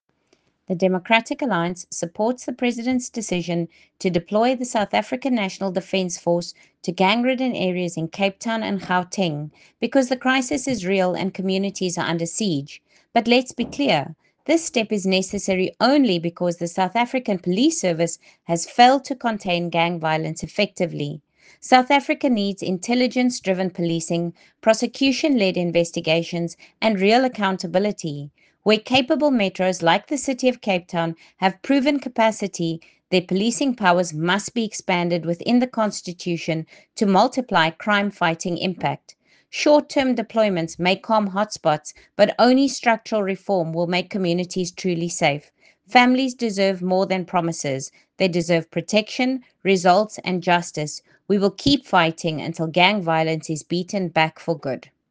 Issued by Lisa Schickerling MP – DA Spokesperson on Police
Afrikaans soundbites by Lisa Schickerling MP.